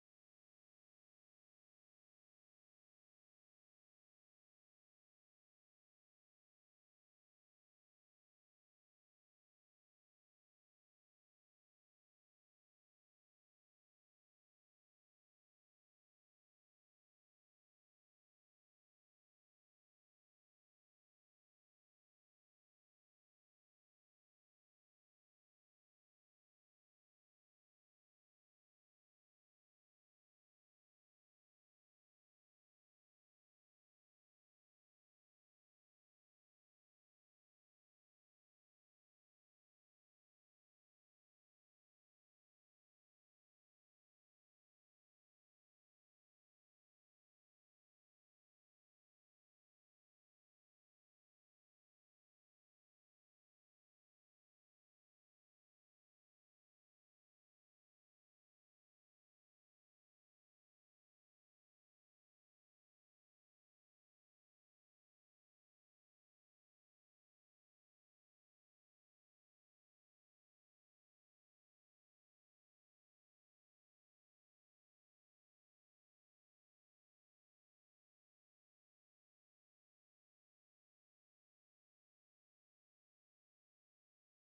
Budapest boat ride sound effects free download